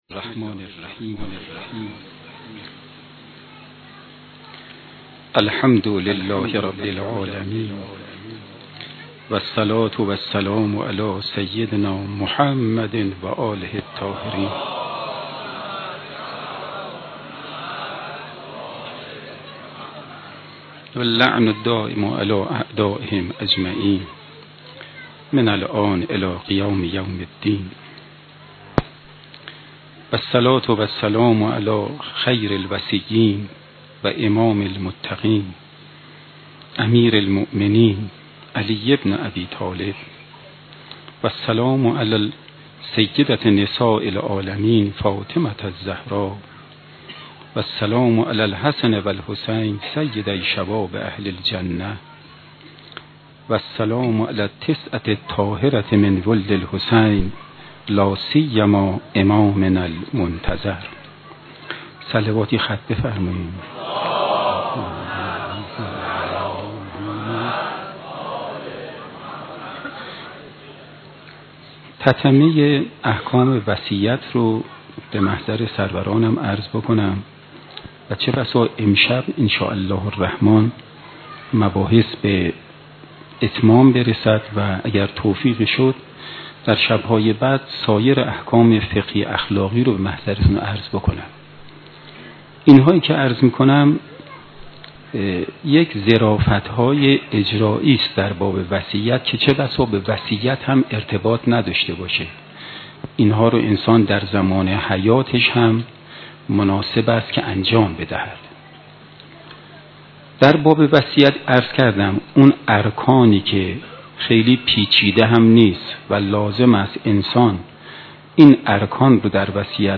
سخنرانی شب تاسوعا